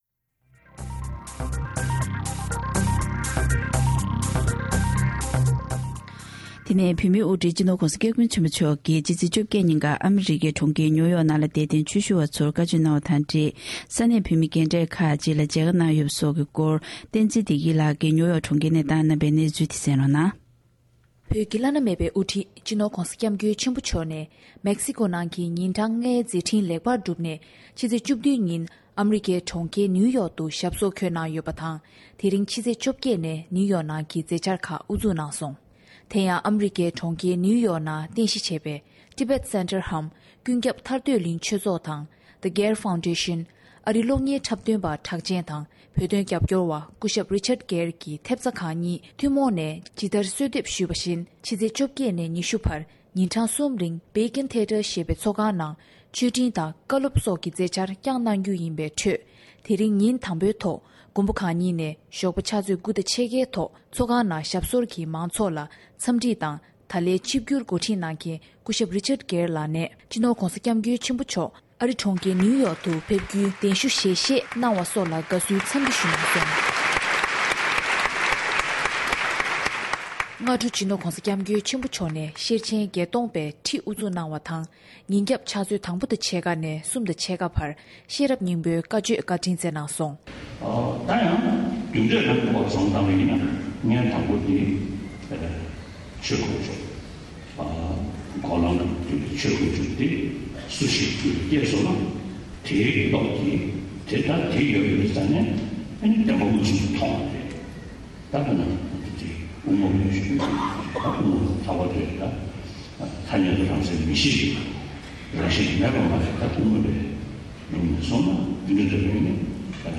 ས་གནས་ནས་བཏང་བའི་གནས་ཚུལ་ལ་གསན་རོགས